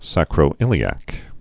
(săkrō-ĭlē-ăk, sākrō-)